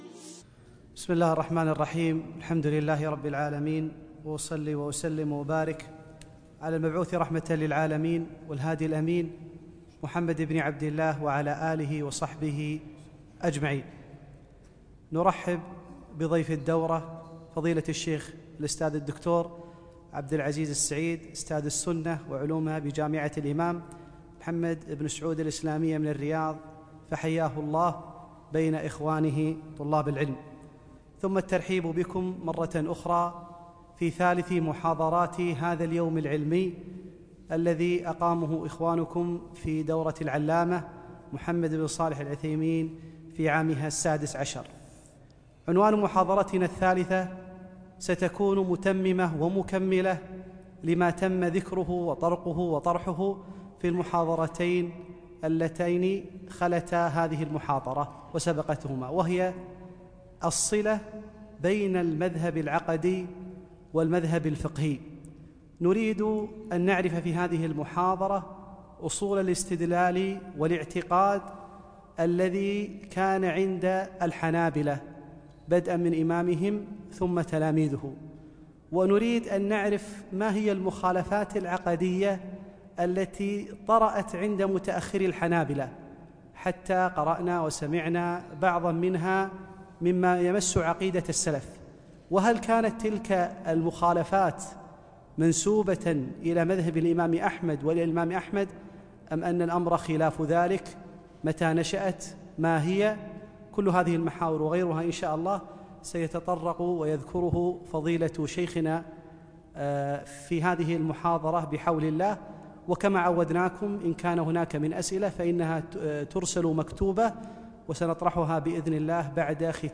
محاضرة - الصلة بين المذهب الفقهي و العقدي